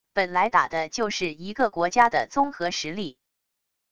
本来打的就是一个国家的综合实力wav音频生成系统WAV Audio Player